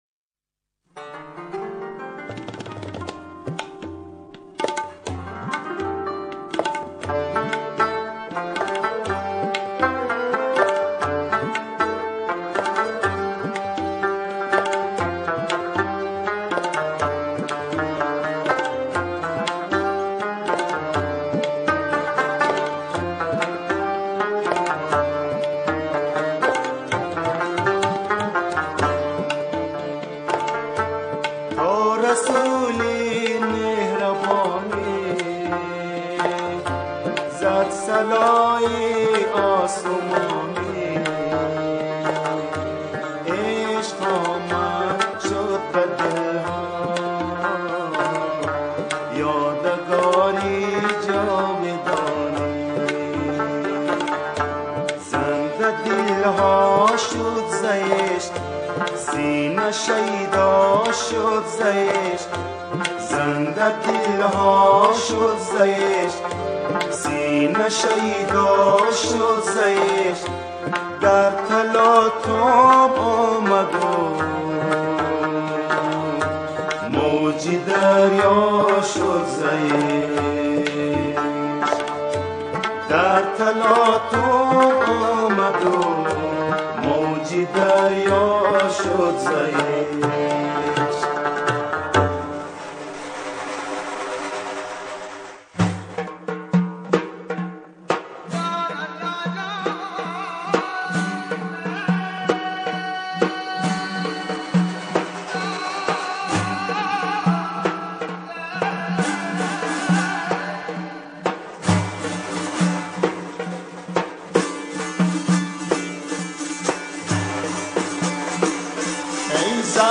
همخوانی
شعر این اثر به دو گویش بلوچی و خراسانی سروده شده است.